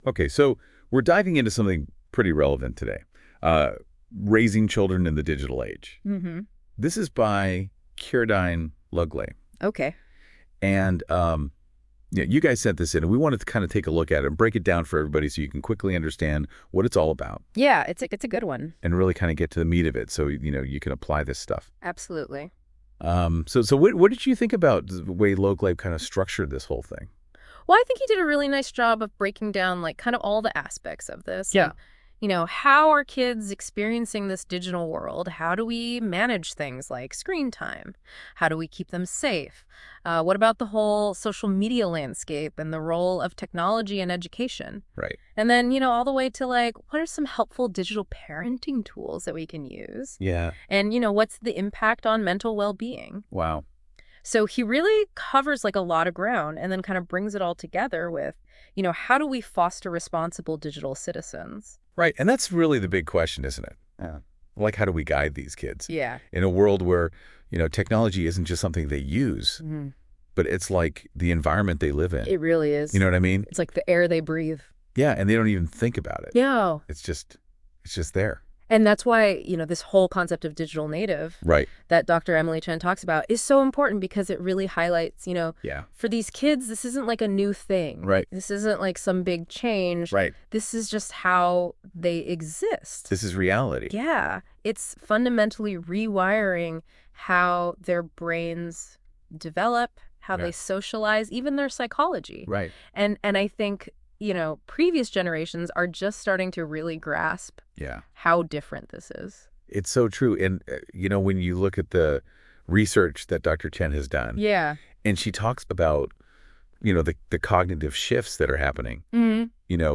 Audio Book -Raising Children in the Digital Age - Tradebit